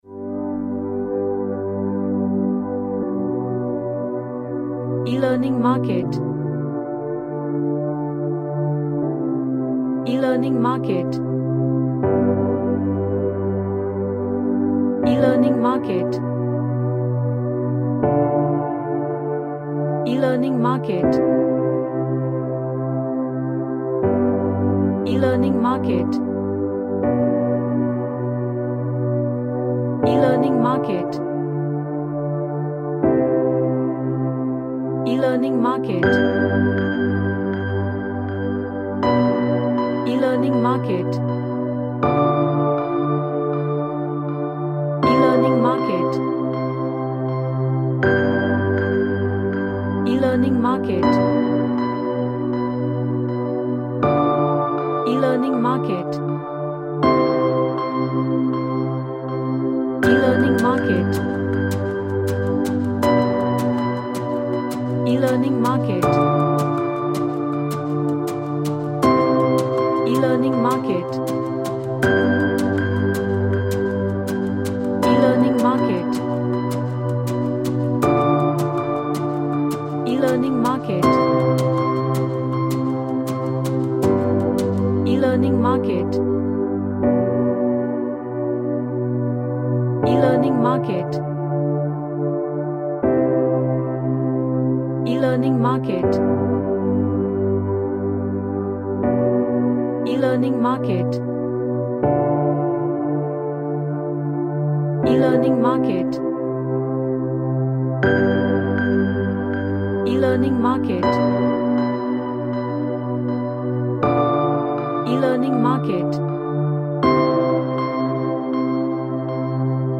A deep relaxing music with guitar strums
Relaxation / Meditation